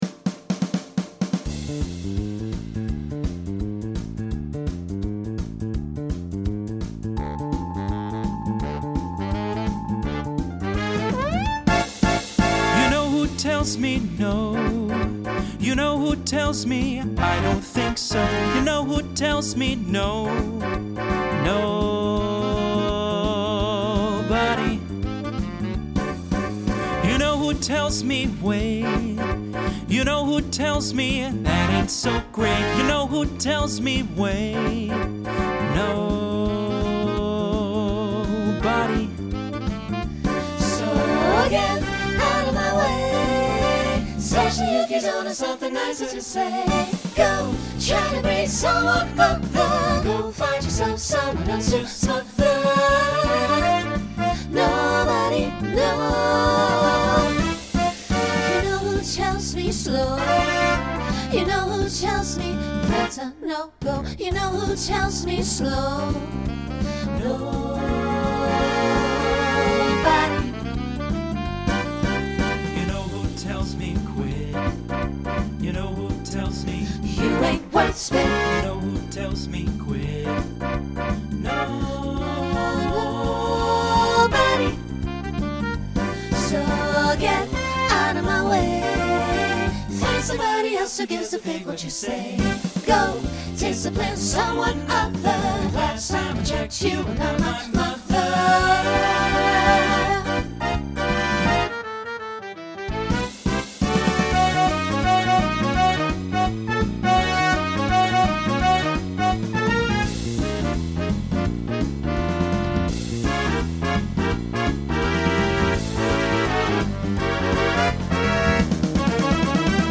Genre Broadway/Film Instrumental combo
Voicing SATB